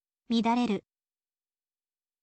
midareru